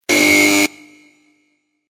Звуки неправильного ответа
На этой странице собрана коллекция звуковых эффектов, обозначающих неправильный ответ или неудачу.
Такой звук неправильного ответа